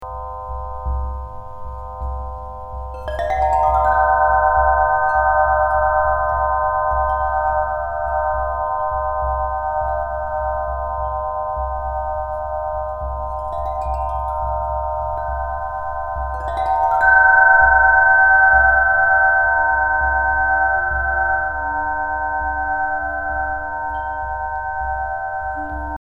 resonance-extrait-4-tubalophone.mp3